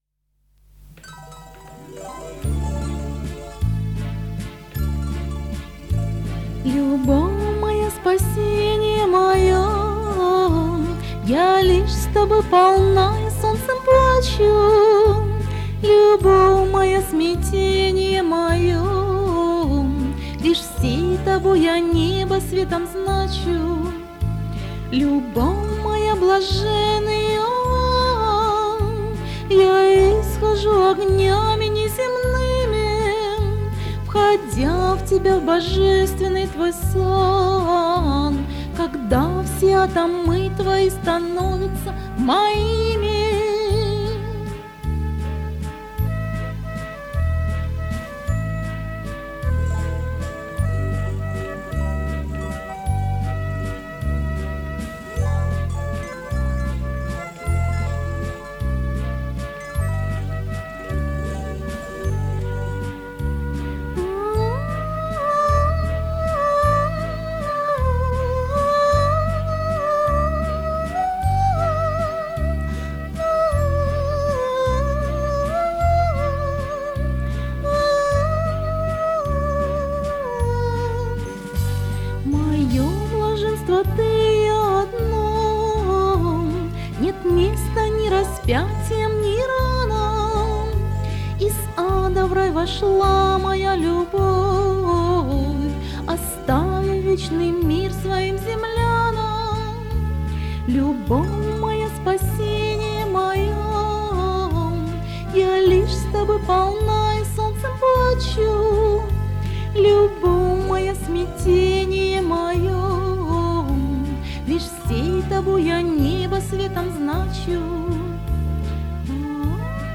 Мистическая музыка Духовная музыка